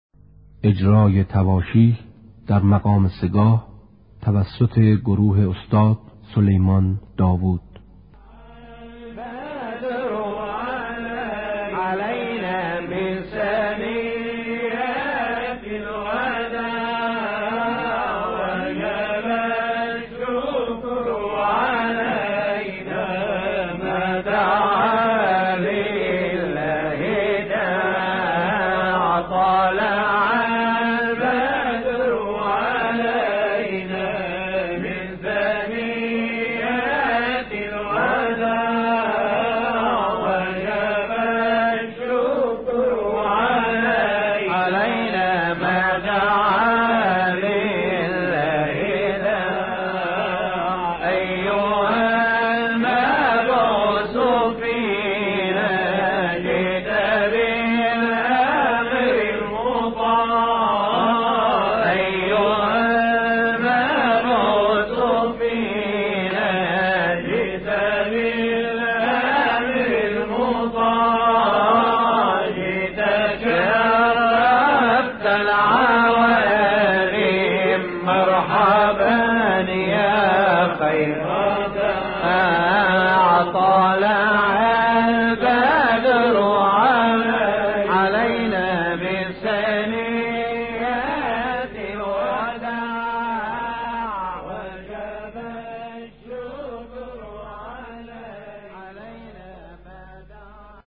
تواشیح در مقام سه گاه
segah-Tavashih.mp3